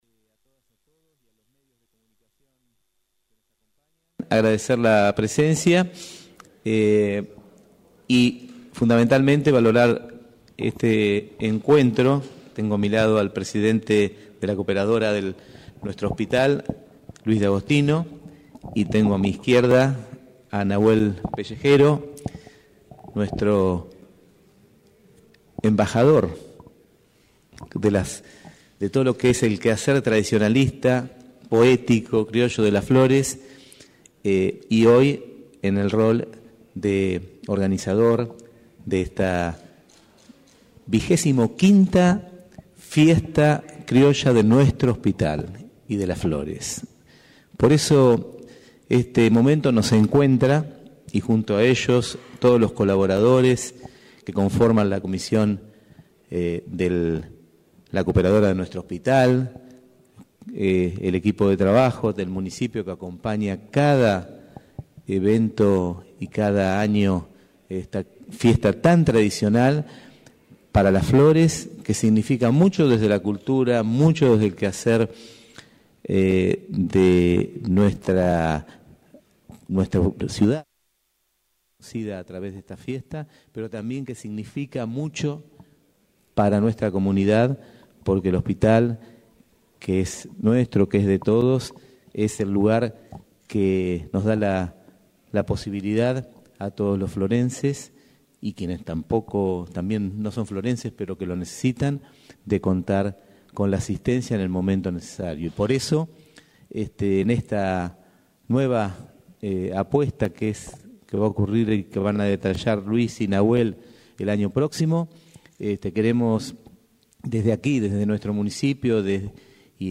Esta mañana, en el Salon Rojo de la Municipalidad, se realizó la presentación oficial de la 25° Fiesta Criolla a beneficio del Hospital Zonal General de Las Flores.